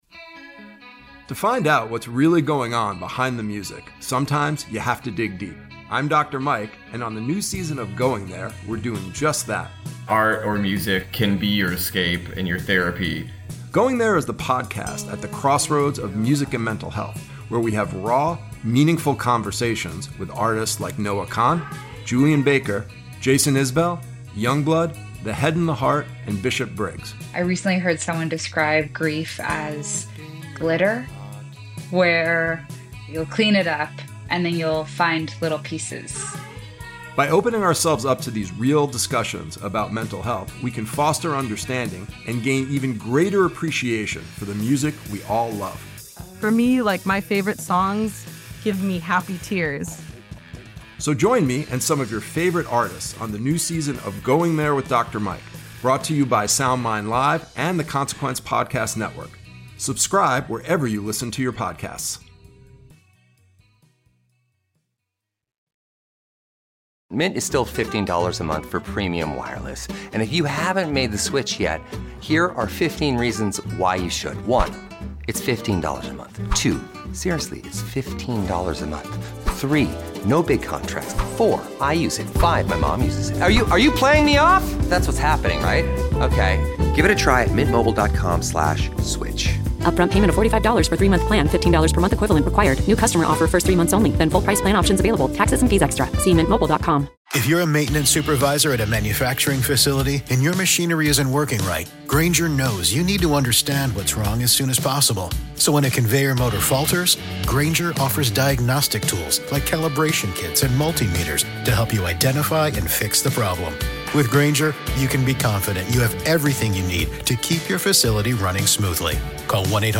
Two ARMY best friends